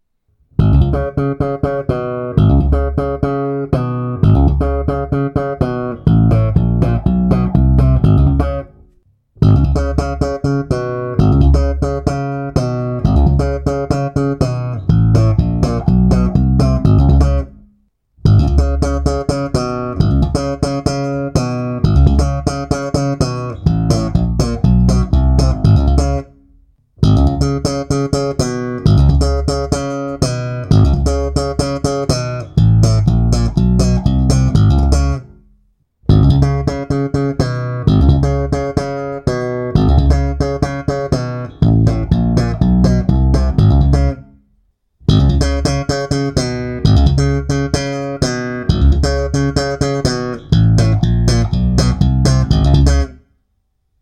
Všechny nahrávky jsou bez dodatečných úprav. Neodstraňoval jsem šum, neupravoval ekvalizaci, jen jsem nahrávky znormalizoval.
Nahrál jsem stupnici nahoru a dolů pro posouzení ovlivnění jak hlubokých, tak vysokých tónů.